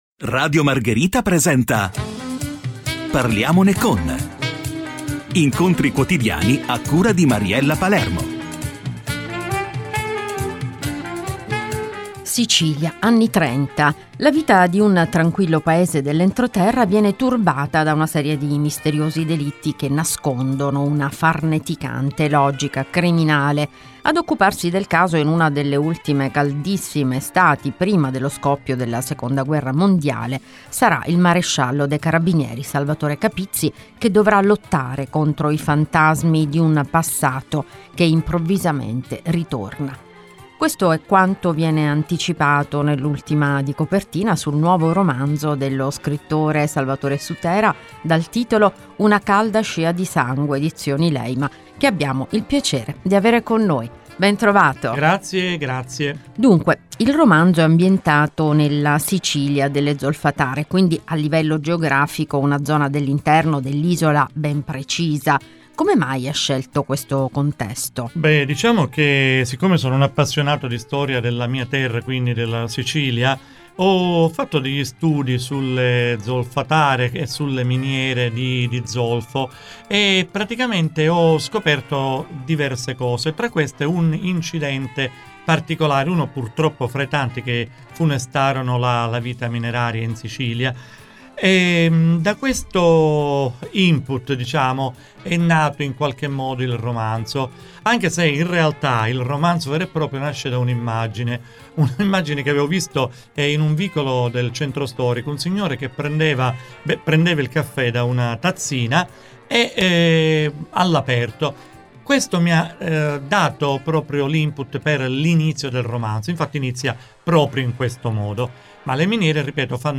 Radio Margherita – Parliamone con… – Intervista